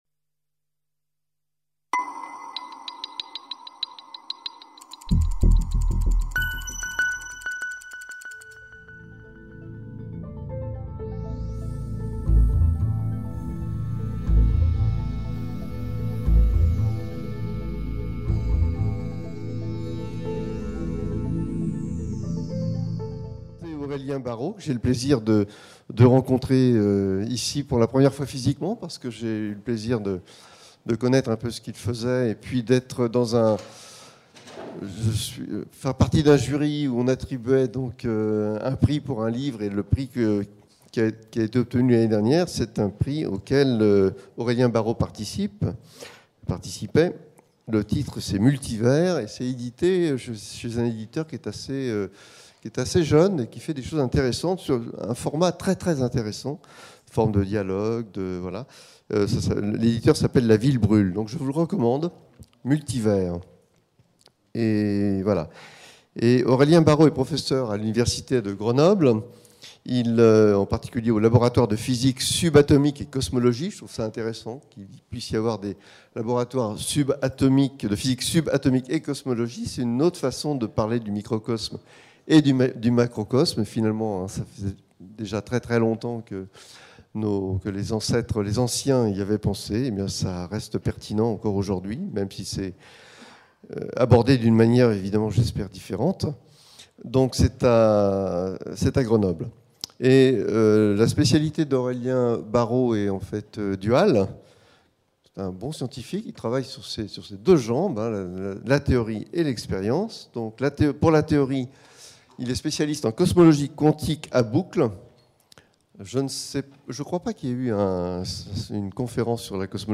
Conférence donnée à l'IAP le 7 février 2012 par Aurélien BARRAU, astrophysicien au Laboratoire de Physique Subatomique et de Cosmologie de Grenoble. Dans cette conférence, je proposerai une introduction simple à la cosmologie d'aujourd'hui et aux concepts physiques qui la sous-tendent. De la relativité générale à la théorie des cordes, en passant par les trous noirs et la gravitation quantique, nous en viendrons à interroger le statut de notre univers lui-même.